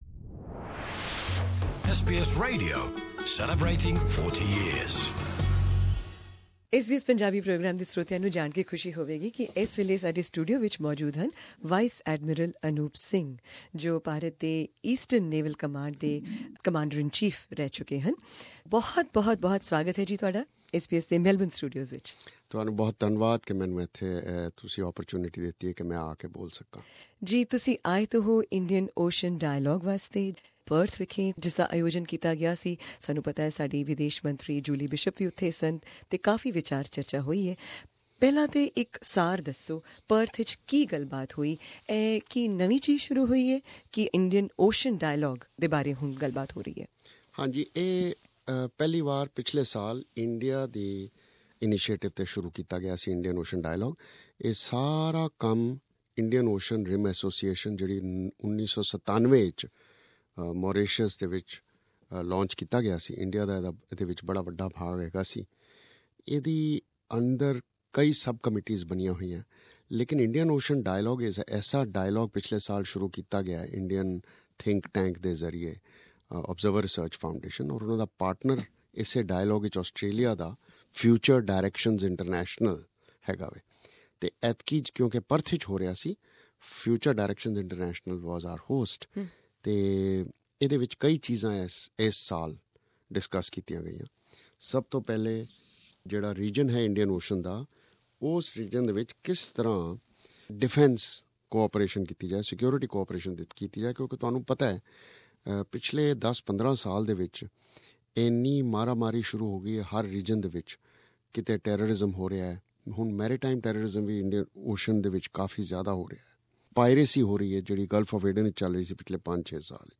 You can hear an interview in English